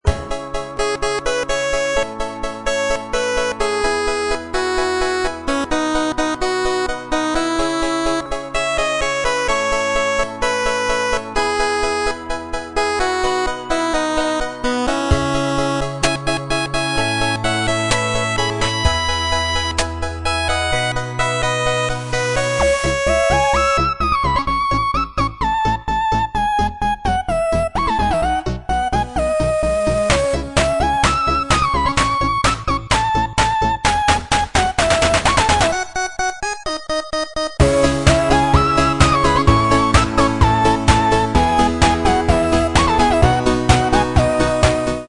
Tono RETRO REALIZADO EN 8 BITS